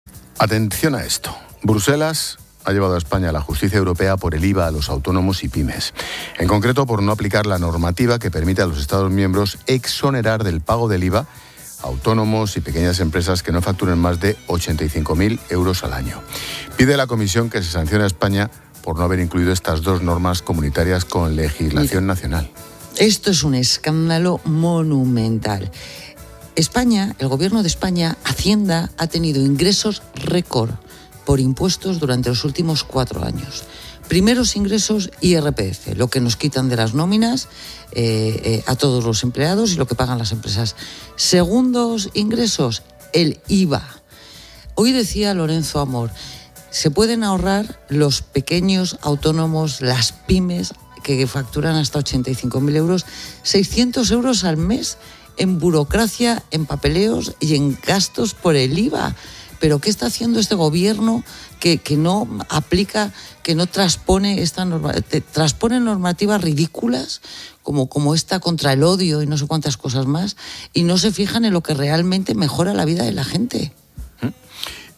Expósito aprende en Clases de Economía de La Linterna con la experta económica y directora de Mediodía COPE, Pilar García de la Granja, sobre Bruselas lleva a España a la justicia europea por el IVA de los autónomos